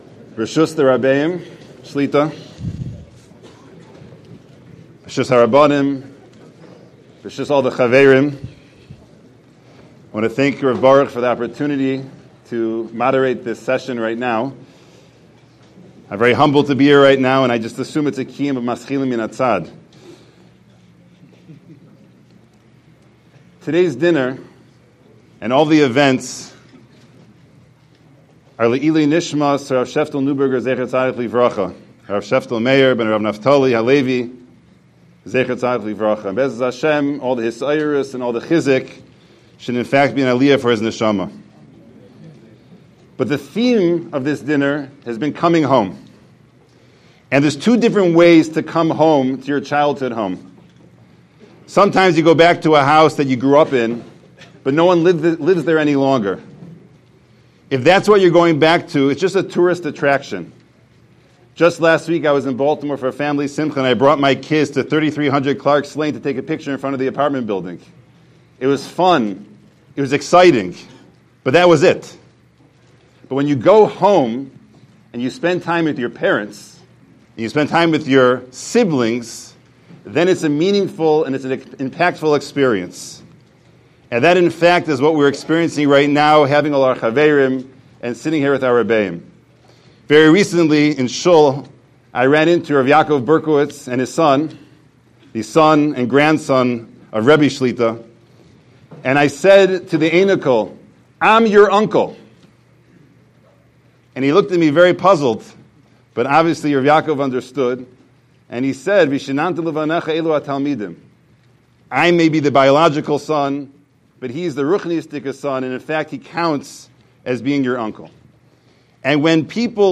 Oneg-Shabbos-with-Rebbeim-NIRC-Dinner-1-9-22.mp3